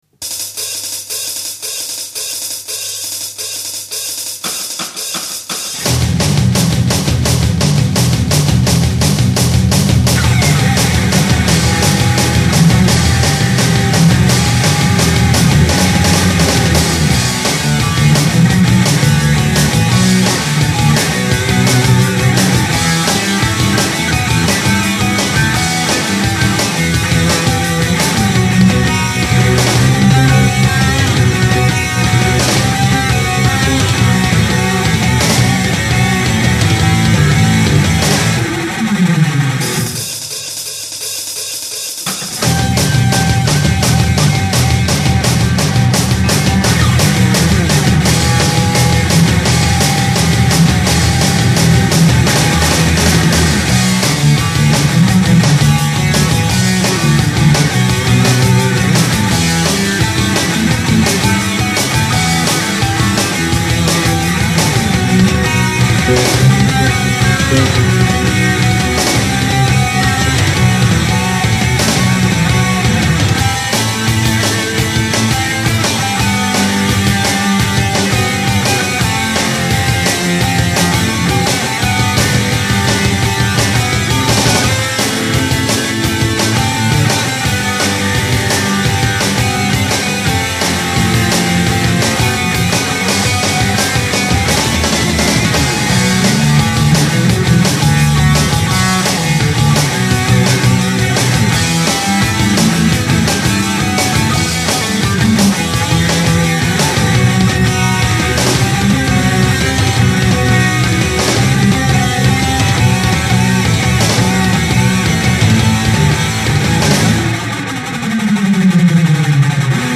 MP3音源はリマスターしました